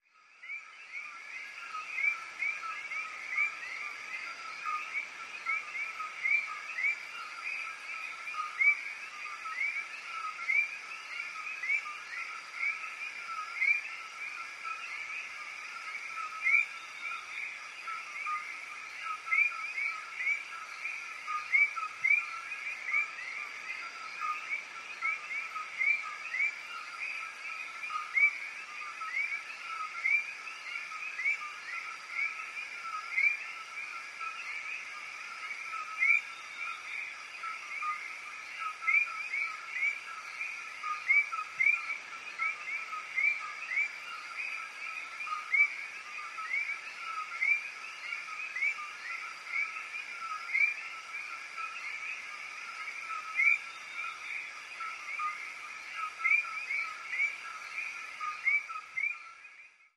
Jungle
Bird Atmospheres, Jungle Atmosphere.